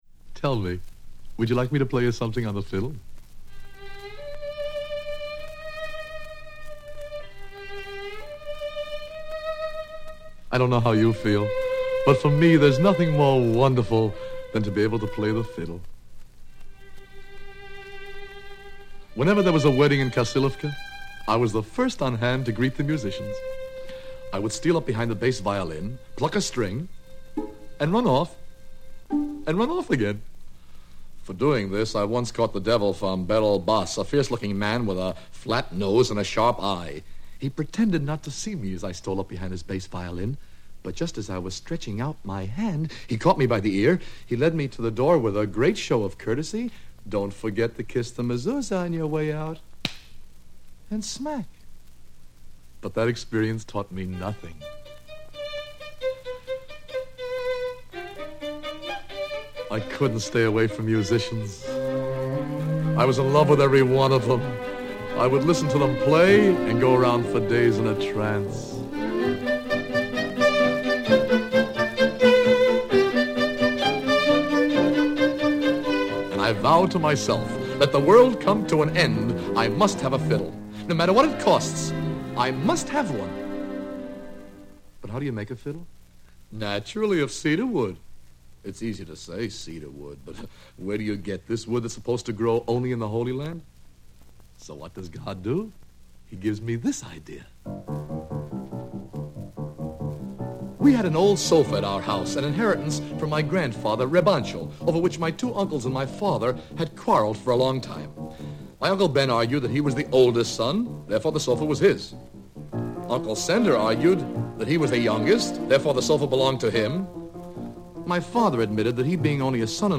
The Fiddle (Sholem Aleichem, translation by Julius and Frances Butwin, adaptation by Howard Da Silva, music by Serge Hovey, violin solo by Oscar Shumsky) read by Howard Da Silva, from Sholem Aleichem’s Tales from the Old Country as told by Howard Da Silva. 12-inch 78rpm album cover, designer and artist unknown.